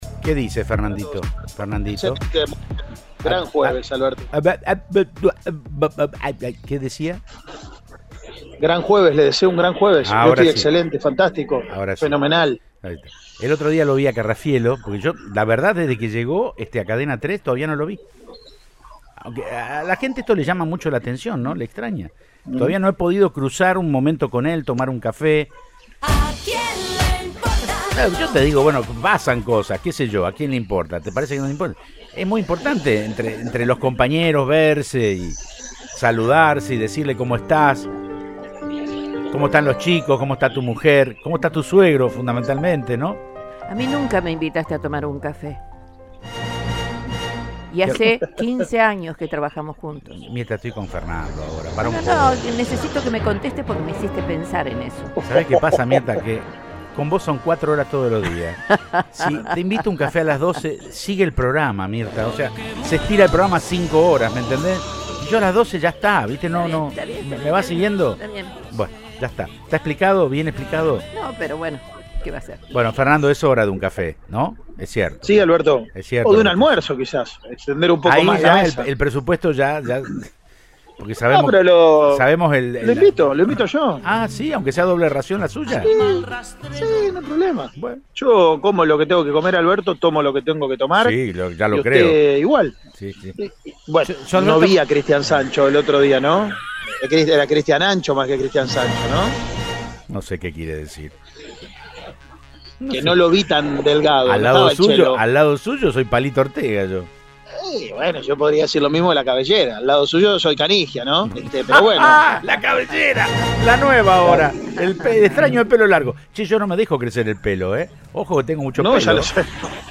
dialogó con el móvil de Cadena 3 Rosario, en Siempre Juntos